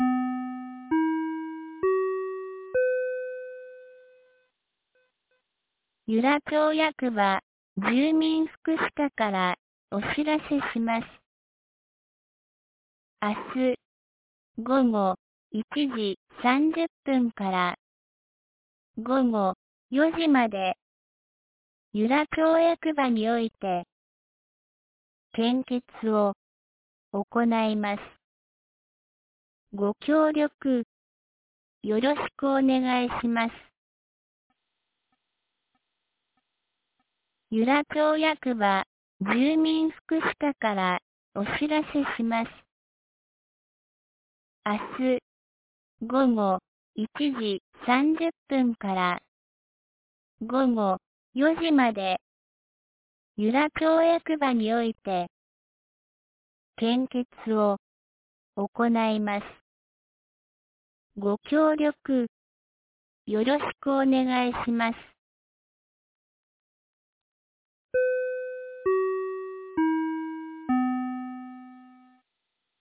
2024年08月19日 12時21分に、由良町から全地区へ放送がありました。